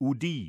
udir tool or pull
Rm-sursilv-udir.flac.mp3